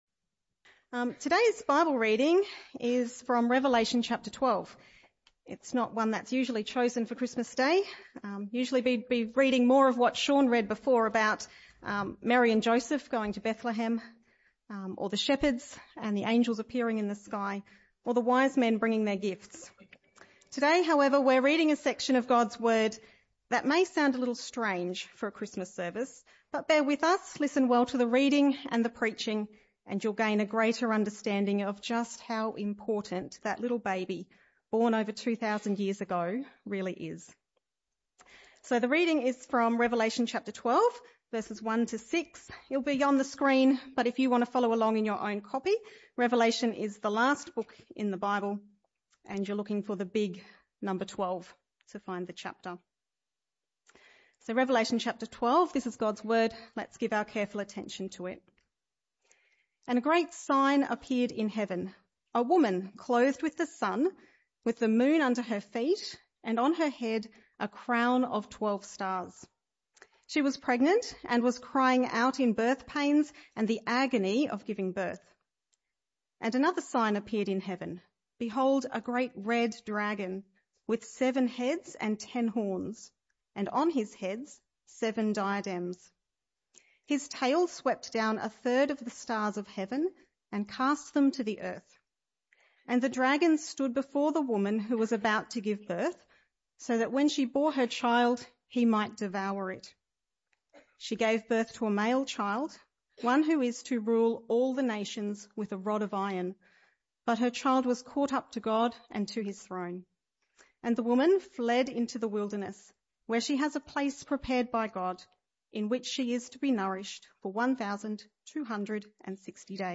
This talk was the last in the Christmas Series entitled Joy, Far As The Curse Is Found!